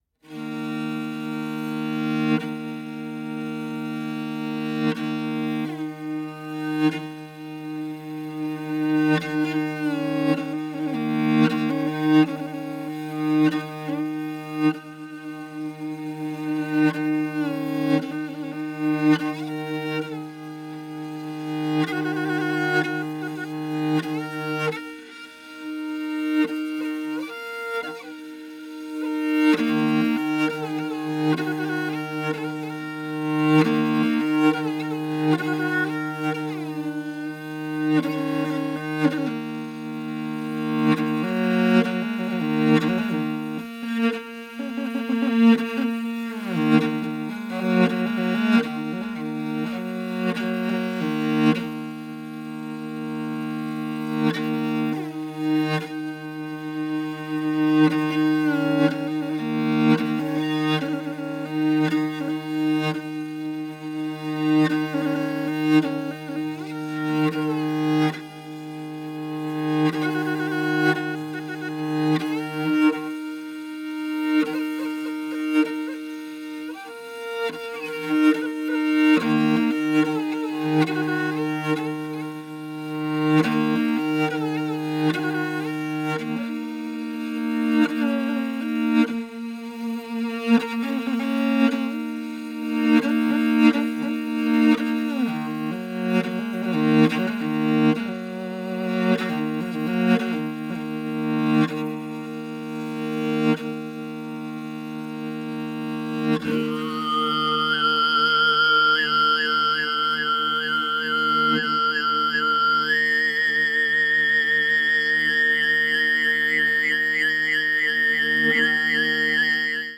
ホーミー、ヤトガ（モンゴル箏）、馬頭琴、トプショールの響きを楽しめるモンゴル伝統音楽集！
演奏も録音も非常に洗練されていて、土着的な風合いを滲ませた無駄のないサウンドが心地良く響いてくる良作です！